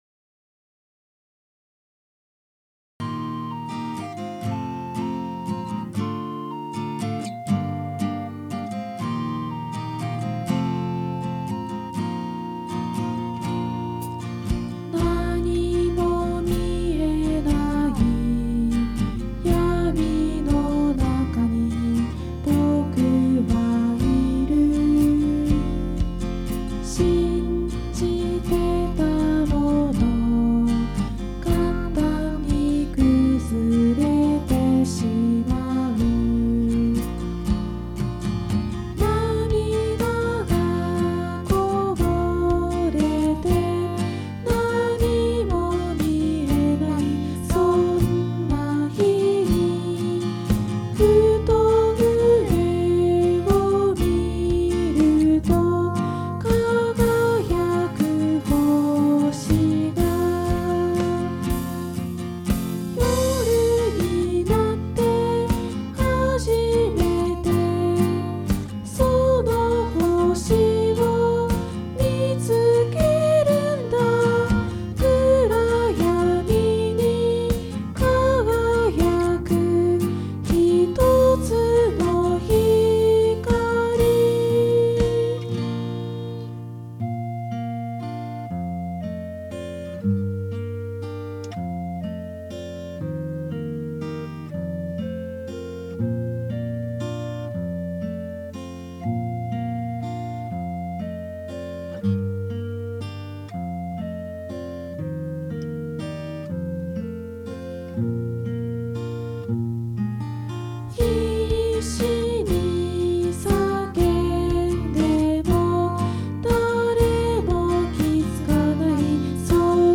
2022年音声劇＿れんしゅう用音源